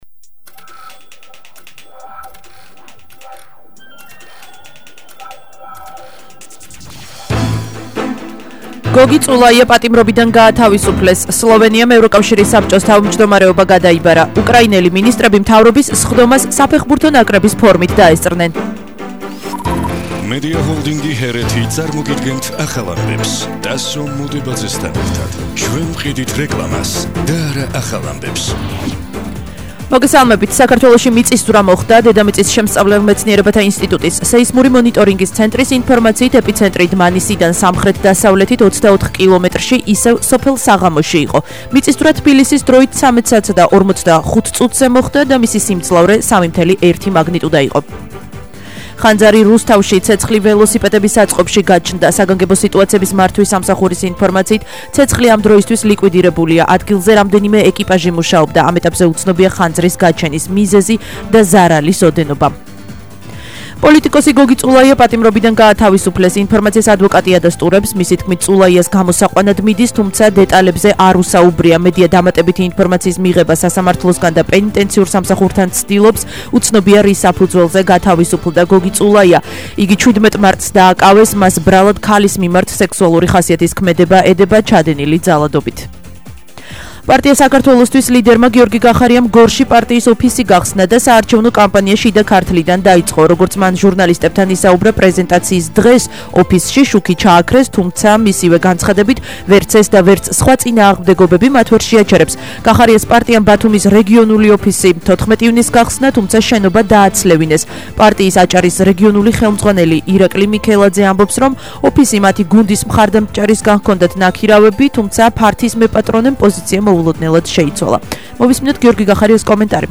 ახალი ამბები 15:00 საათზე –1/07/21 - HeretiFM